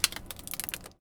R - Foley 200.wav